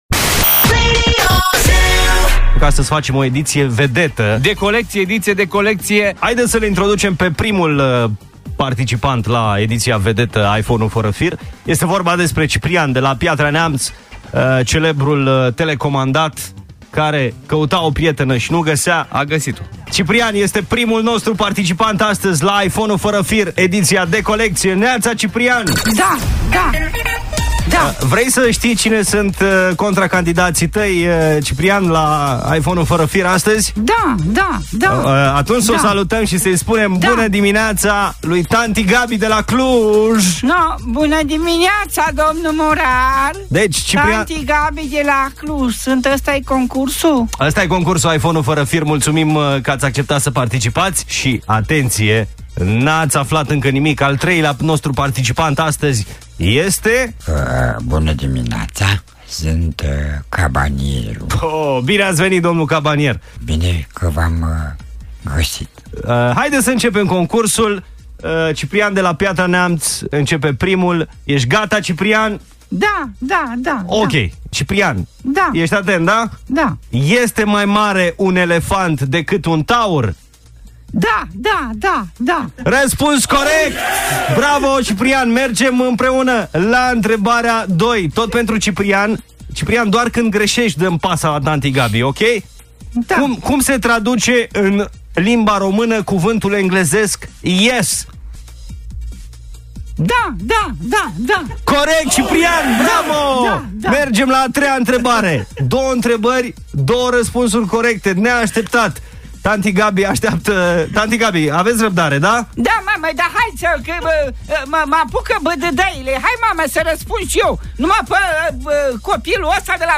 In dimineata asta am facut o editie speciala a concursului care in fiecare dimineata iti aduce un iPhone 4 la Morning ZU. 3 invitati ZUperspeciali au raspuns intrebarilor gazdei concursului, Mihai Morar